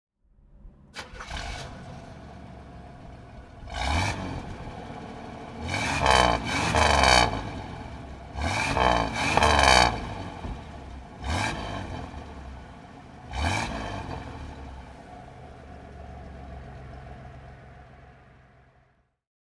Buick Skyhawk (1979) - Starten und Leerlauf
Buick_Skyhawk_1979.mp3